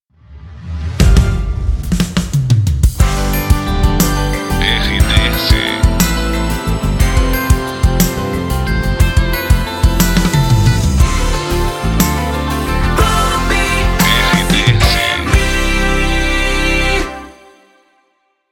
Volta de Bloco Longa